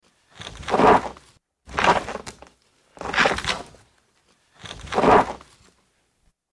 Wood Scrape; Wood Scrape On Gritty Dirt.